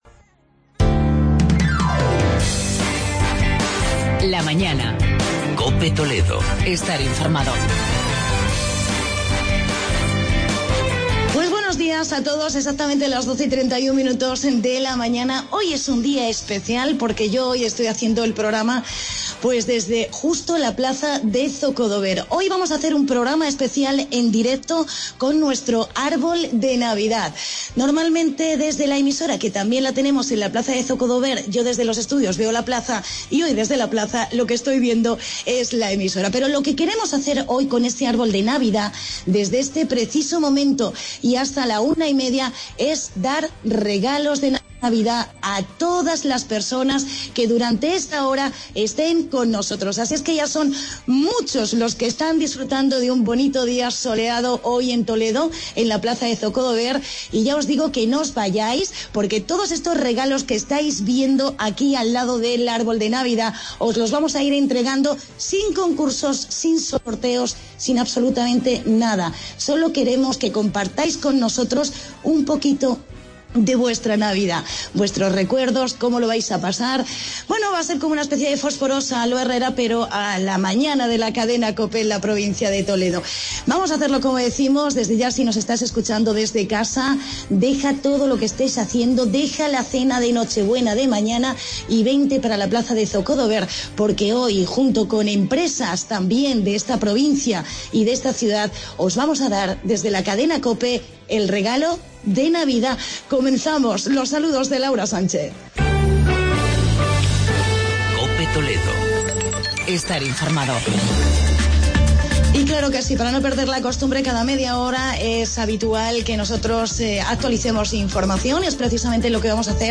Programa especial "Árbol de Navidad" desde la Plaza de Zocodover.